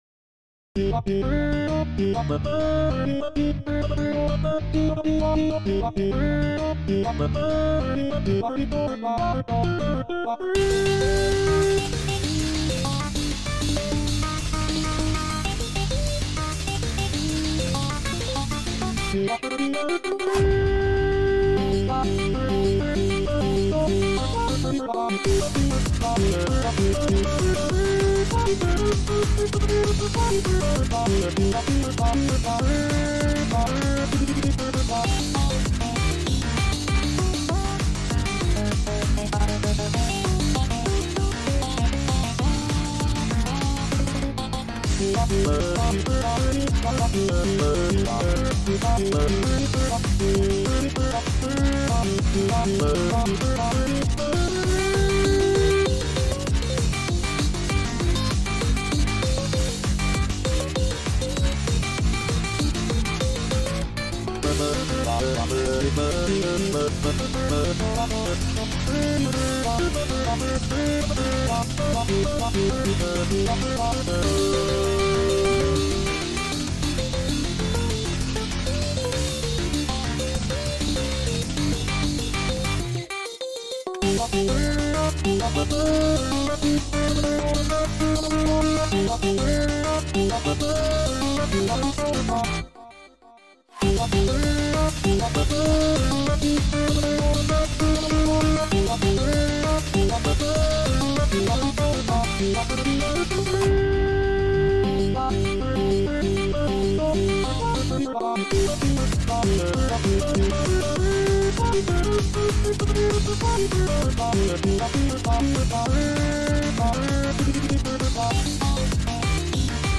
Shedletsky chromatics